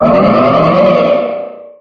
gardevoir-mega.ogg